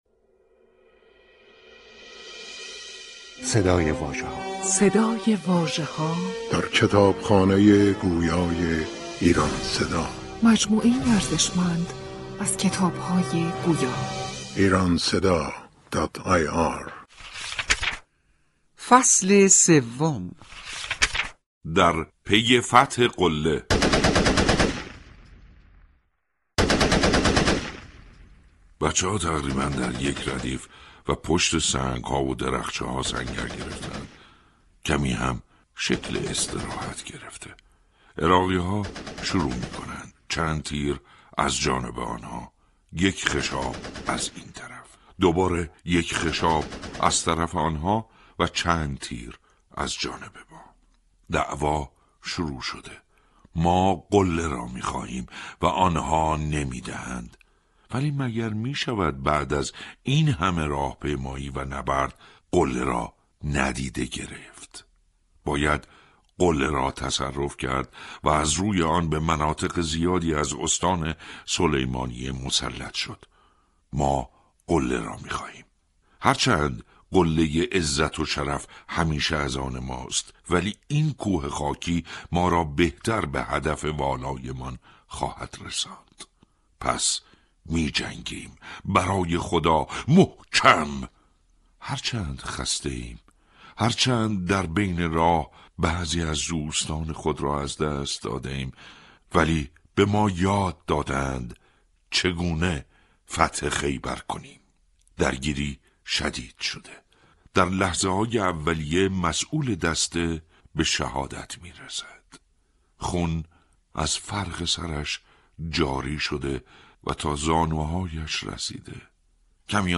کتاب گویای «سینای شلمچه» منتشر شد
کتاب گویای «سینای شلمچه» تهیه و بر روی پایگاه کتاب گویای ایران صدا در دسترس علاقه‌مندان قرارگرفته است.